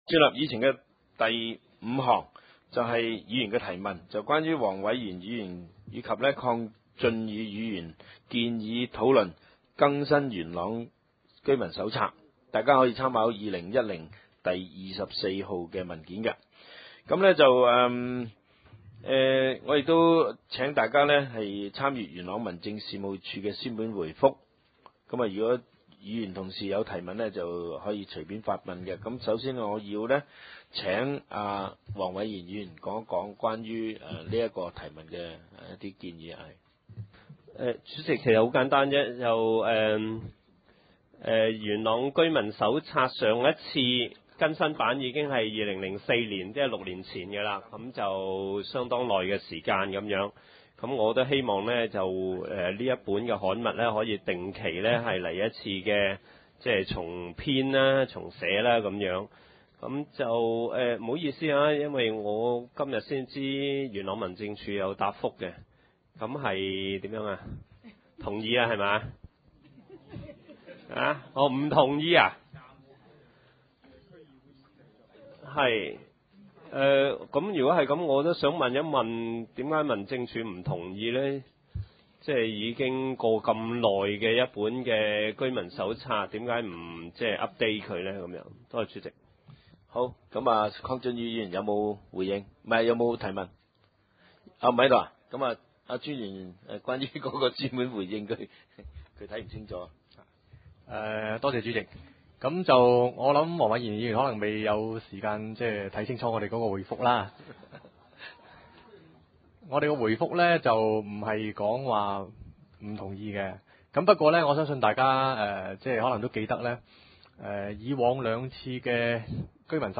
點：元朗橋樂坊二號元朗政府合署十三樓元朗區議會會議廳